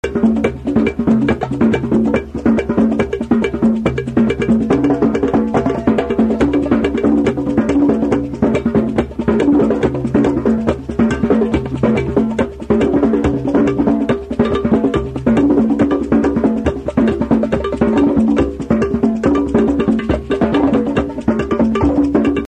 These are 20 second drum circle jam ring tones.